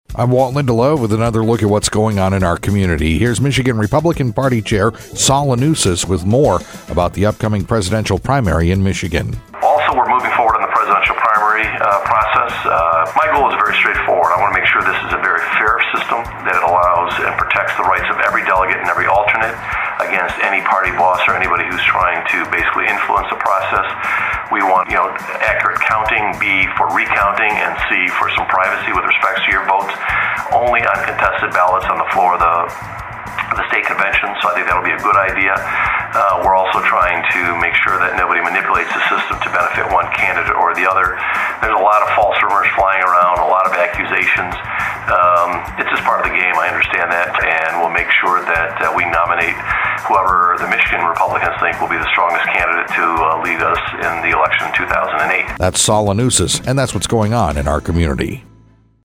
Saul Anuzis, Chair, MI Republican Party – Update for voters heading into the MI Primary.